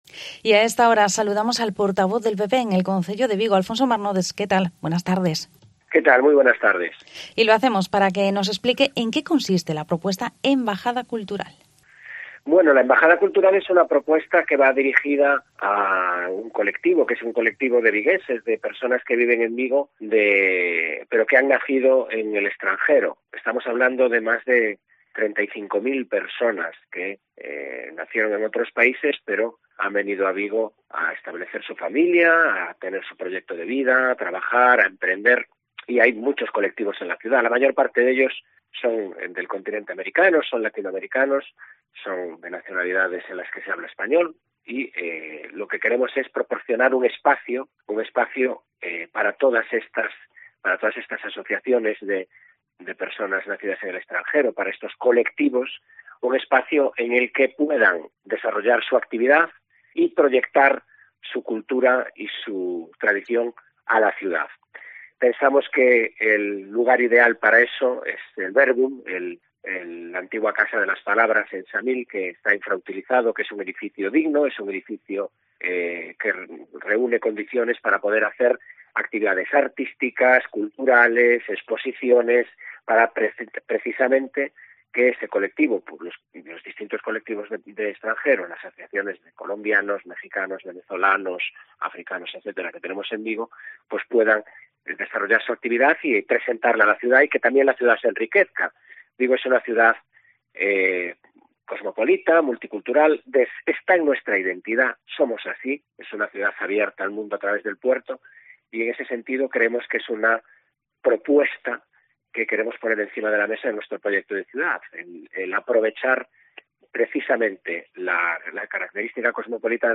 ENTREVISTA
Hablamos con el portavoz del PP en Vigo, Alfonso Marnotes, también sobre la reclamación que han hecho en las últimas horas para que se incrementen las patrullas de atestados de la Policía Nacional y también sobre la recogida de firmas en Vigo contra los indultos a los presos del procés.